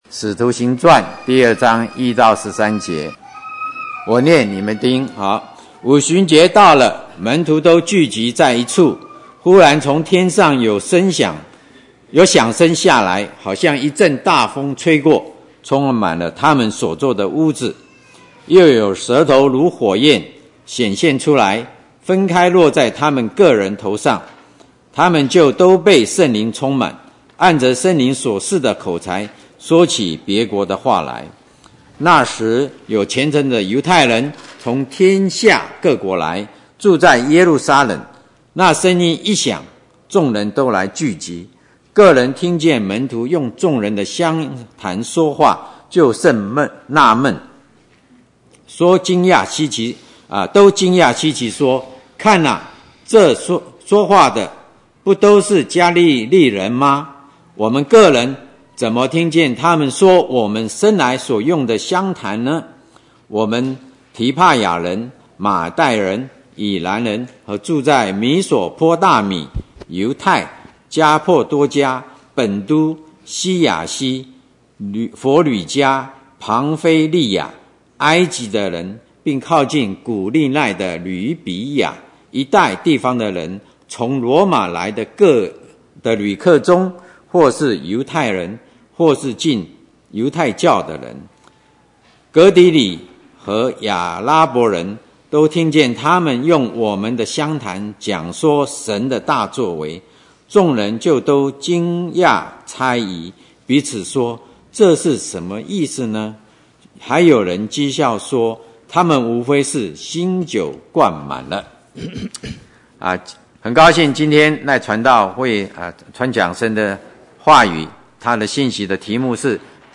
Bible Text: 使徒行傳 2: 1-13 | Preacher